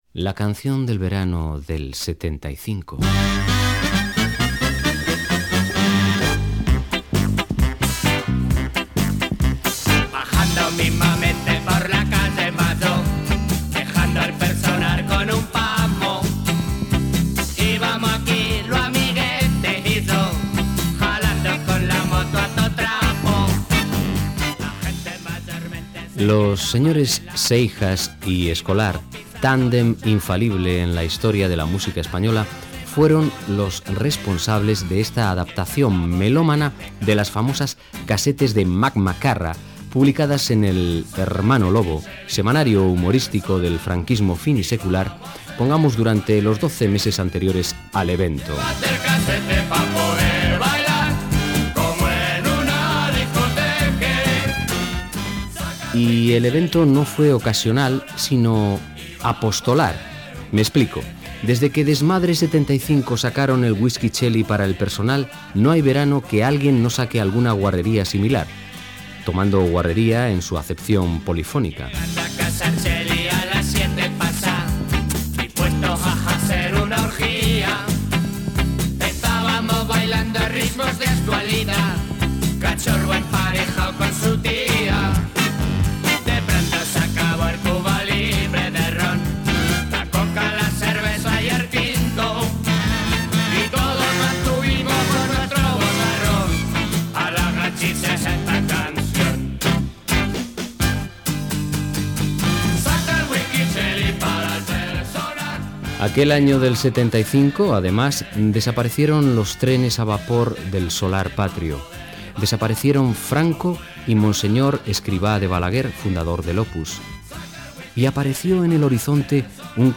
La cançó de l'estiu de l'any 1975 a l'Estat espanyol Gènere radiofònic Musical